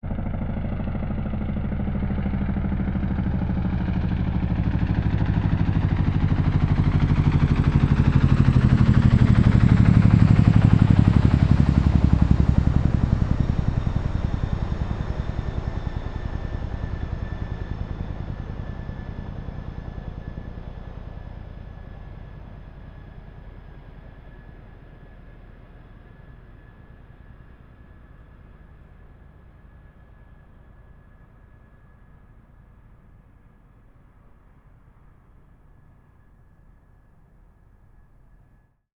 AEROHeli_InsJ_Helicopter_Flyby_Close_03_Chinook.wav